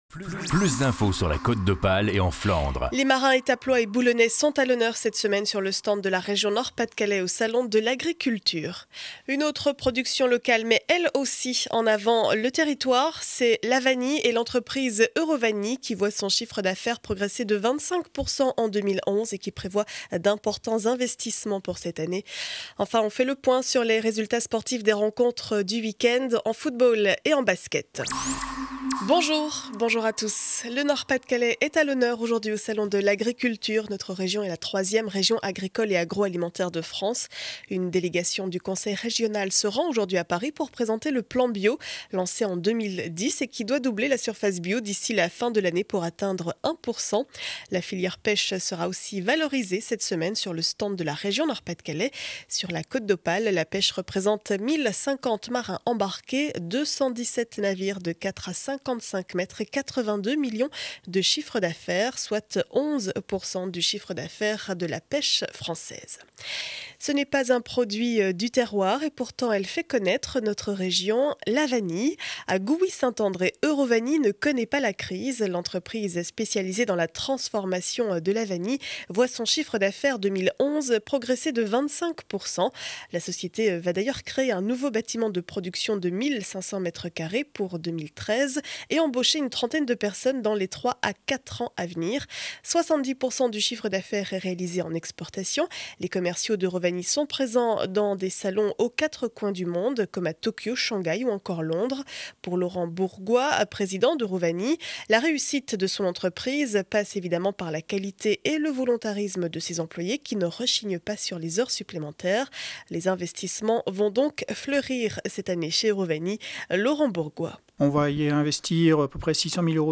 Journal du lundi 27 février 2012 7 heures 30 édition du Boulonnais.